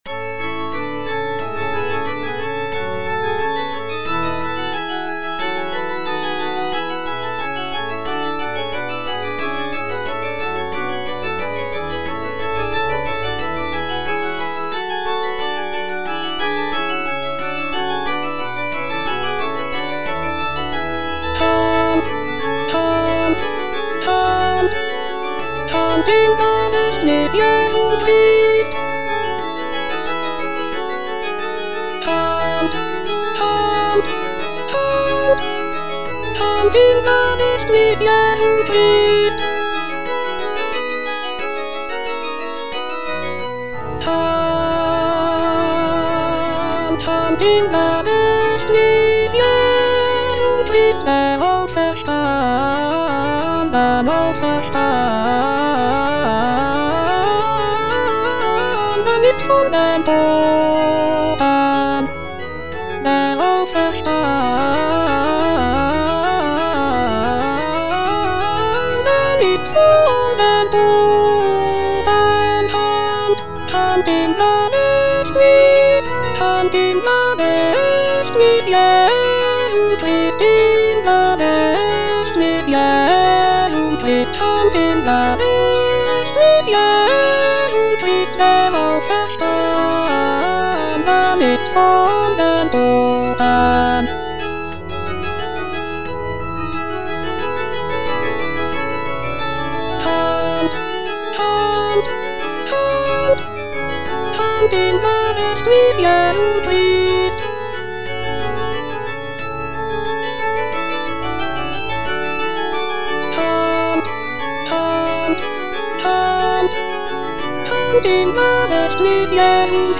Choralplayer playing Cantata
The cantata is known for its intricate vocal and instrumental writing, as well as its rich harmonies and expressive melodies.